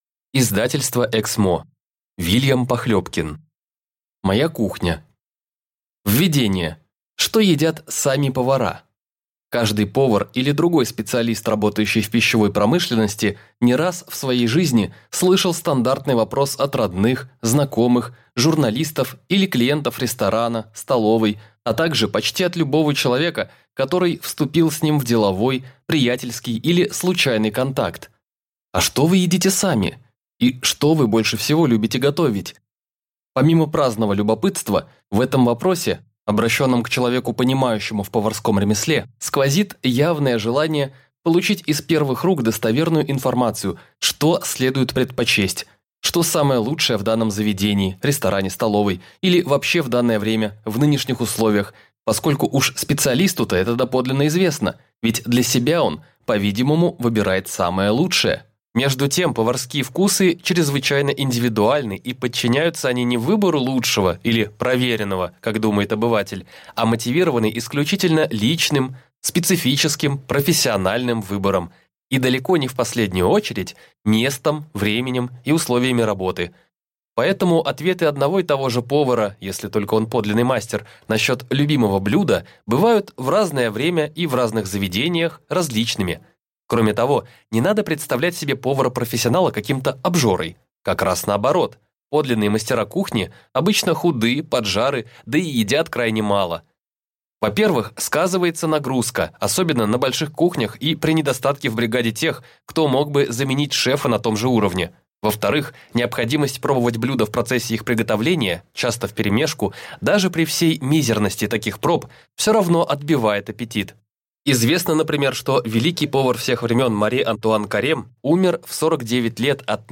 Аудиокнига Моя кухня | Библиотека аудиокниг